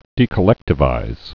(dēkə-lĕktə-vīz)